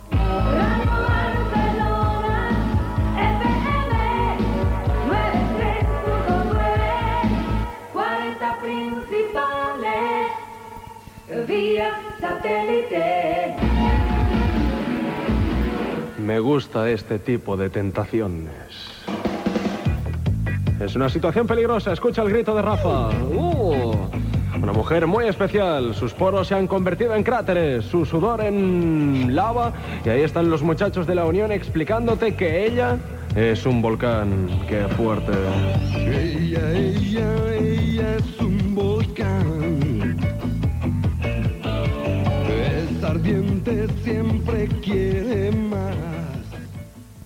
Indicatiu de l'emissora i del programa, presentació d'un tema musical.
Musical
FM